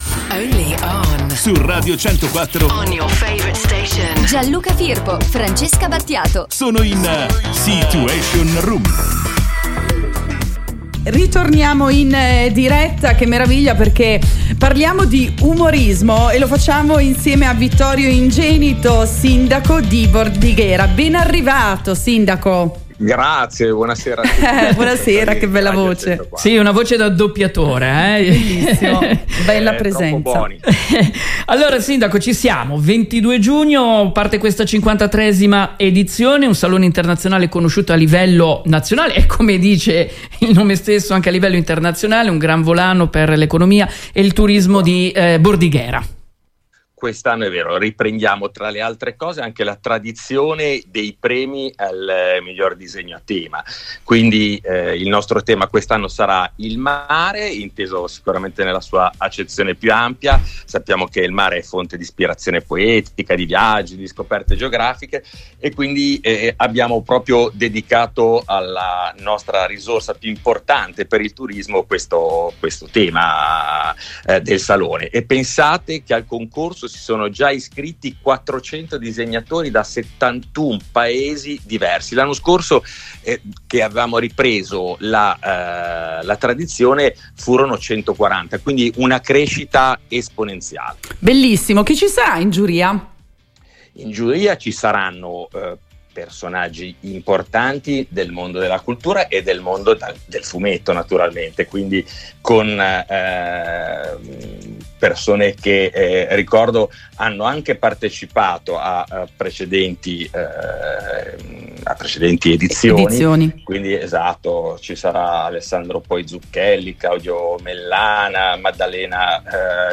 Vittorio Ingenito, sindaco di Bordighera ci parla di umorismo con il salone internazionale dell’umorismo di bordighera